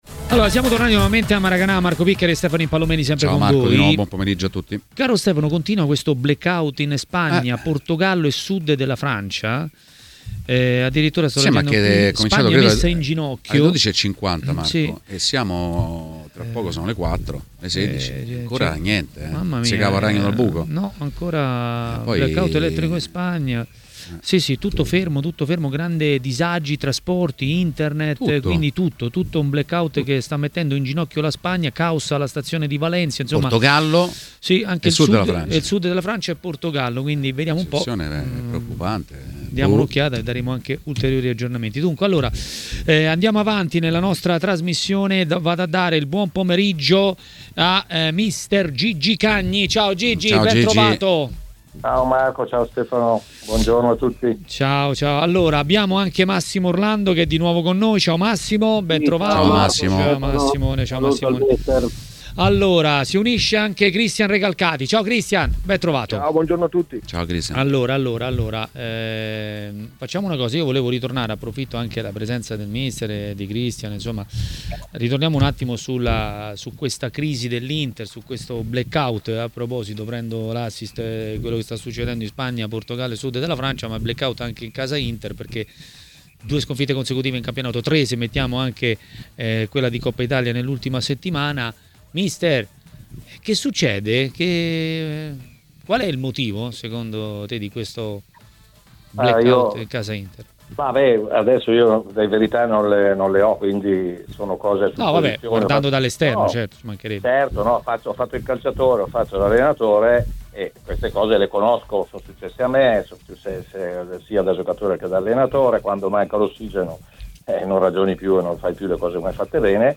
Ospite di TMW Radio, durante Maracanà , è stato Massimo Orlando che ha parlato così della lotta Scudetto.